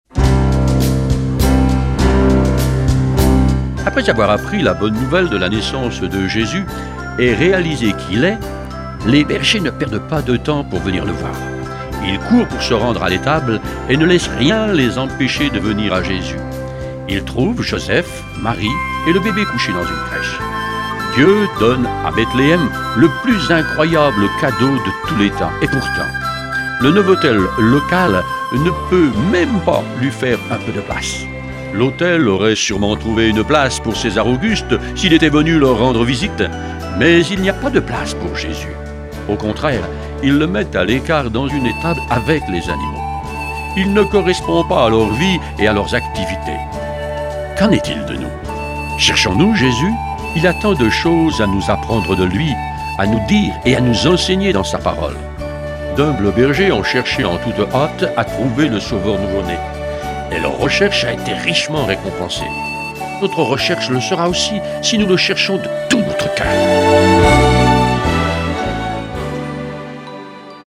Une série de méditations pour le mois de Décembre
Version audio Phare FM :